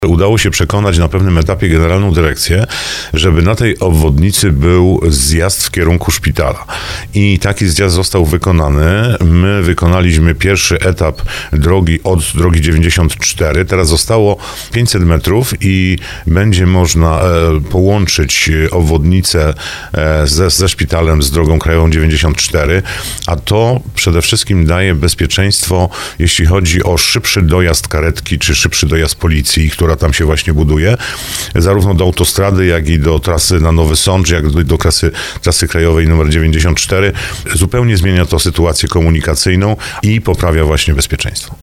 mówił w programie Słowo za Słowo starosta brzeski Andrzej Potępa.